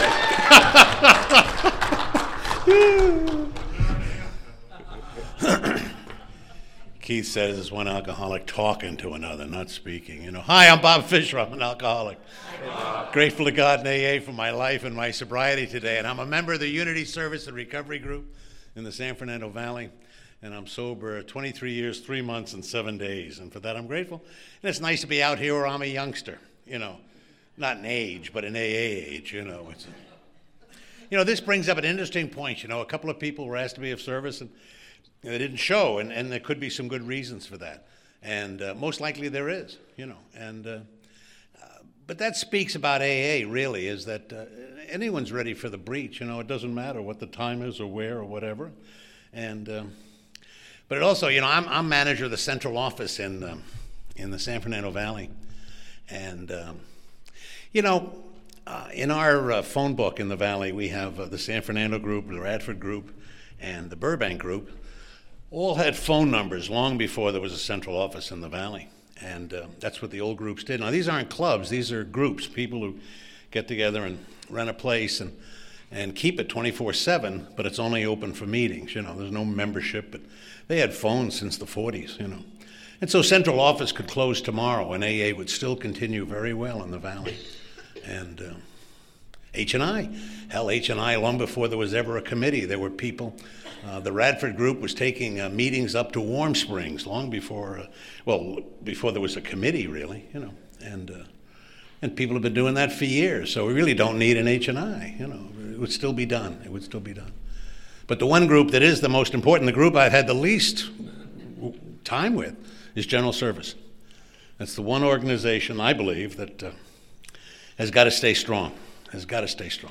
Saturday AA Speaker